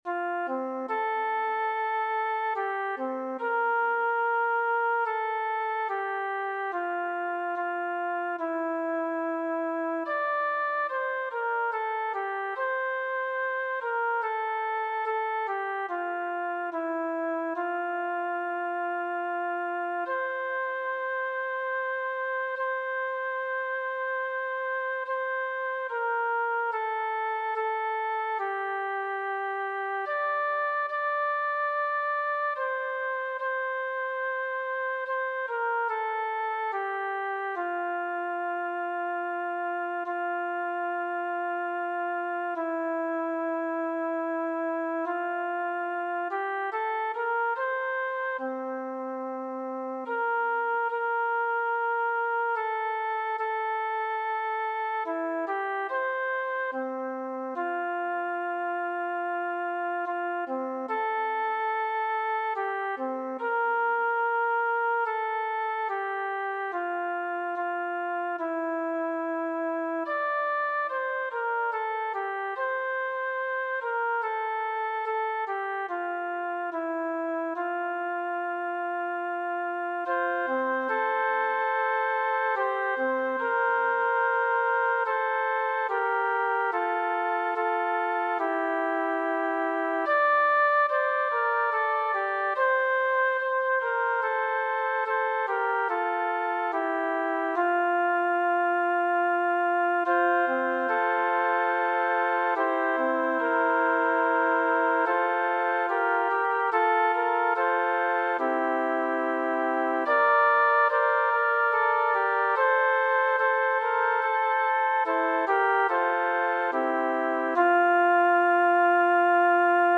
“Himno de recogimiento que se presta para situaciones diversas„